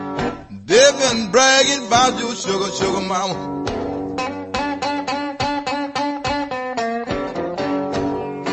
blues_blues.00005.mp3